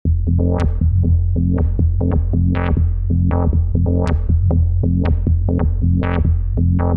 ベースに変化を与える
オートメーションでフィルターを定期的に開くことによって、グワングワンとうなるようなベースを表現しています。